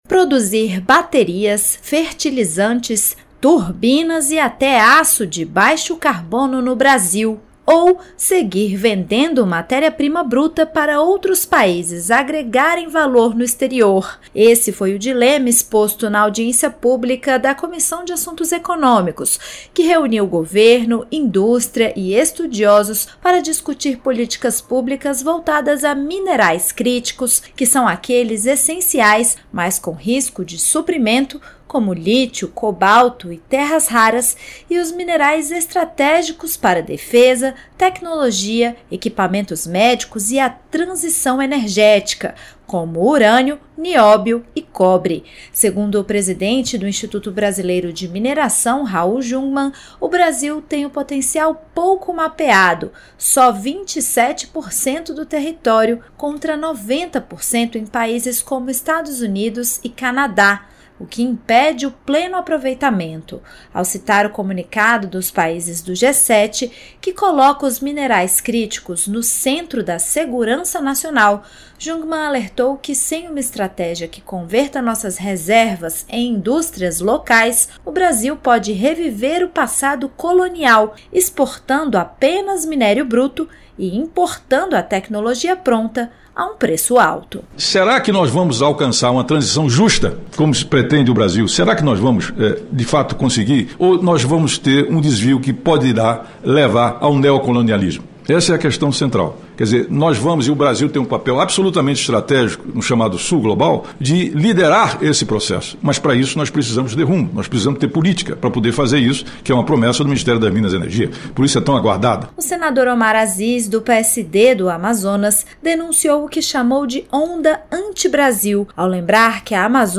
Especialistas alertaram na audiência na Comissão de Assuntos Econômicos nesta terça-feira (1º) que, sem estratégia, o Brasil pode repetir o passado colonial ao exportar minério bruto e importar tecnologia cara.